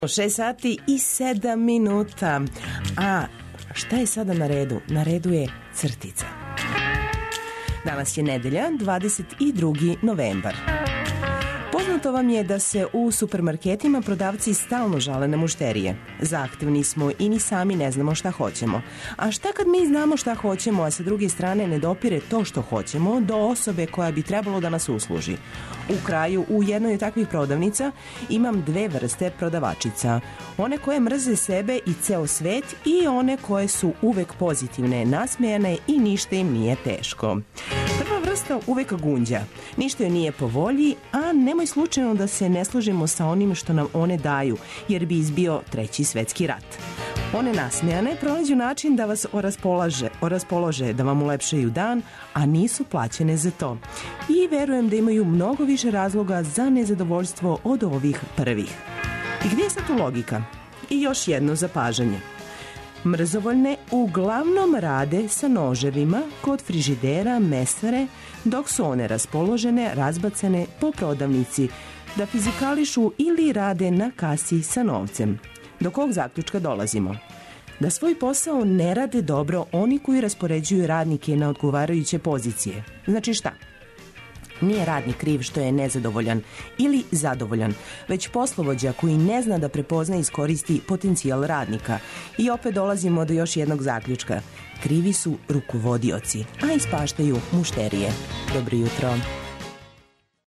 Уранићемо уз Цртицу 202, а затим прелазимо на сервисне, културне и спортске информације прошаране добром музиком и прилозима вредним Ваше пажње.